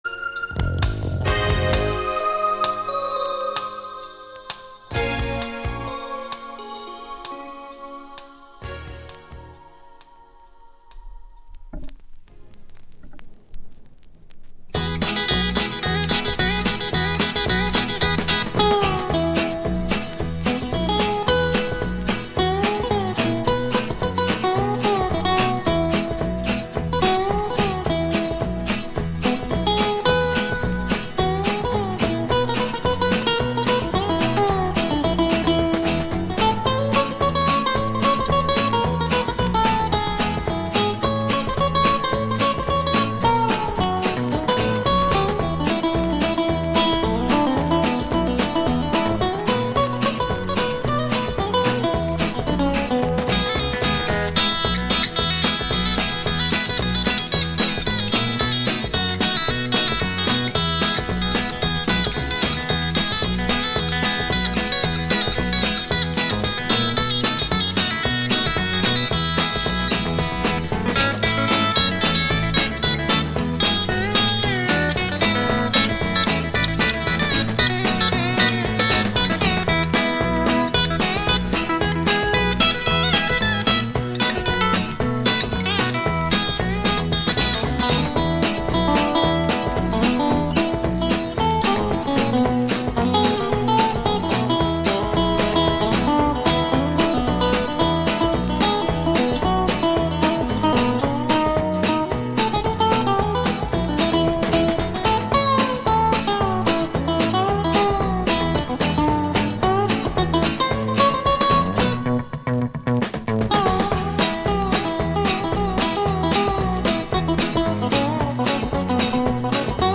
Like a mix-tape on your radio!